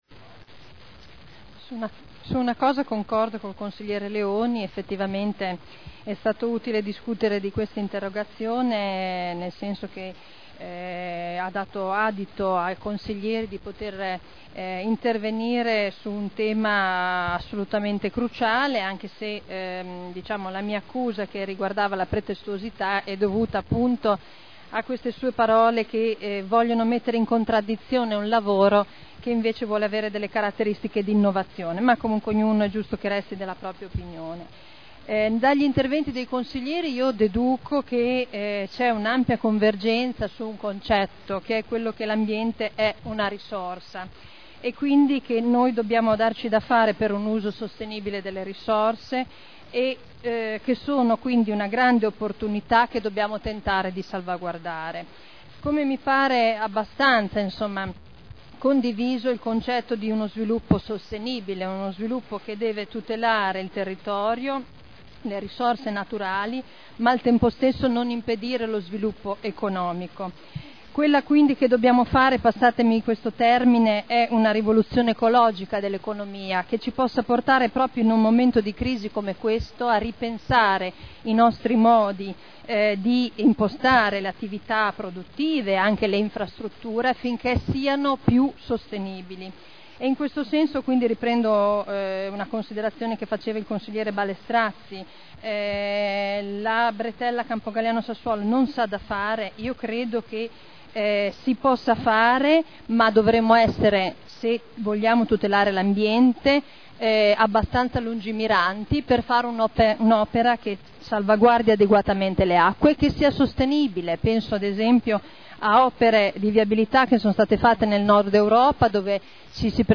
Seduta del 30/11/2009. Politiche ambientali.